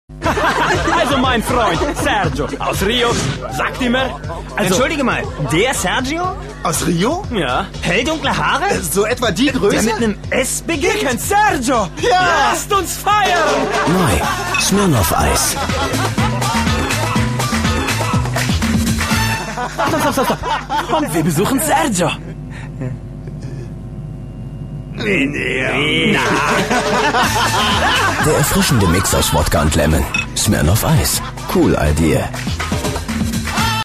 Ob "native" italienisch oder Deutsch mit italienischem Zungenschlag - alles kein Problem.
Sprechprobe: Werbung (Muttersprache):
italien voice over artist